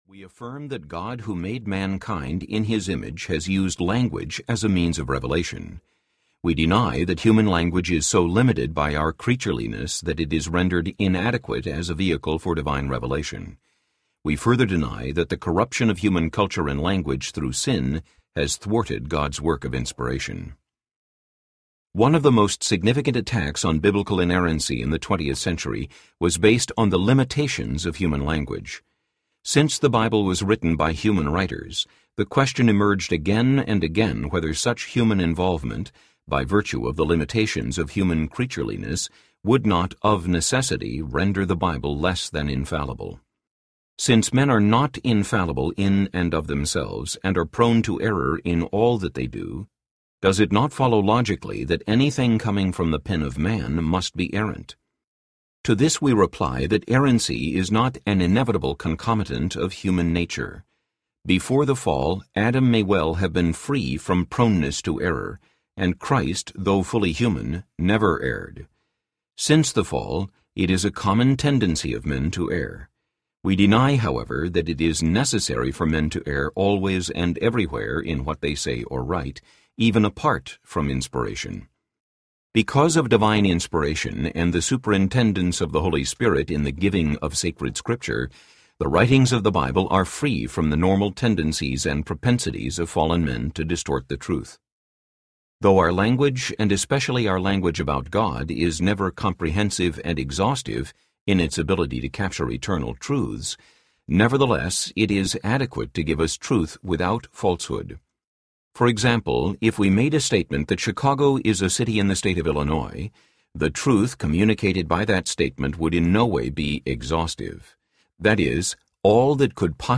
Can I Trust the Bible? (Series: Crucial Questions, Book #2) Audiobook
1.12 Hrs. – Unabridged